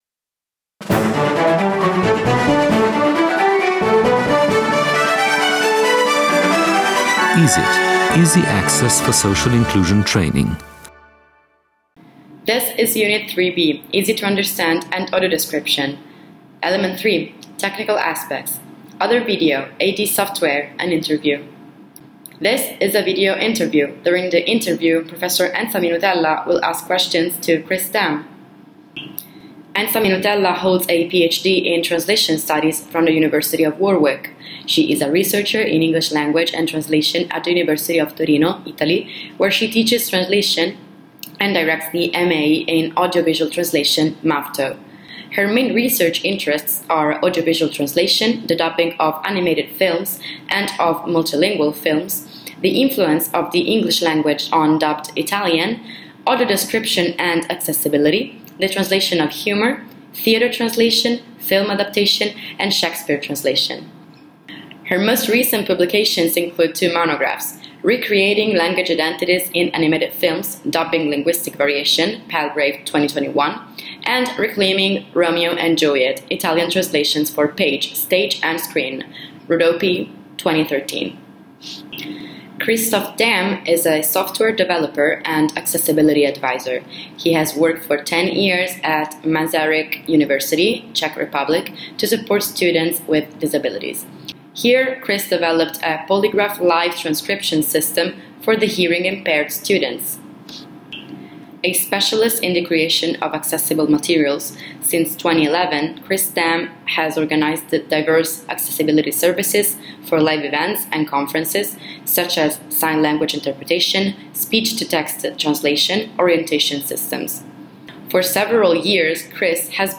3B.3.4. AD software: An interview